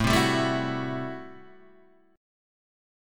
A Minor 13th
Am13 chord {5 3 5 2 5 2} chord